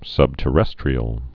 (sŭbtə-rĕstrē-əl)